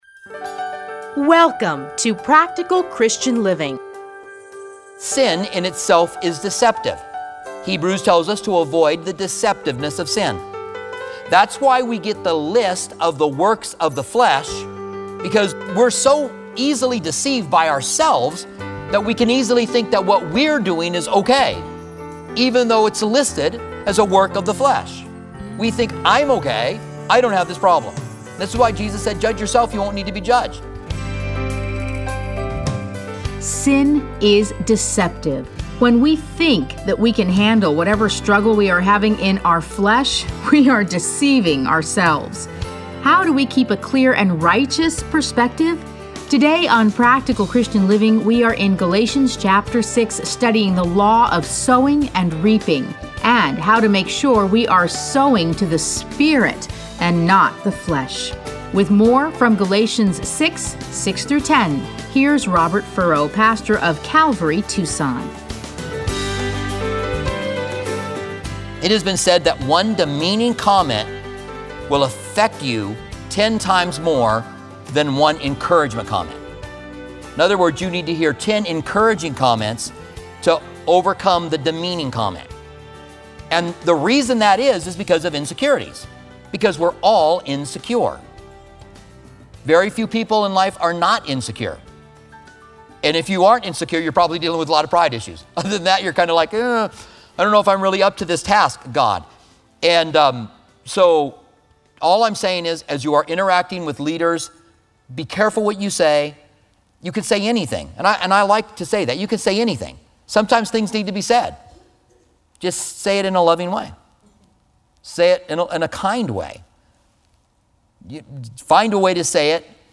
Listen to a teaching from Galatians 6:6-10.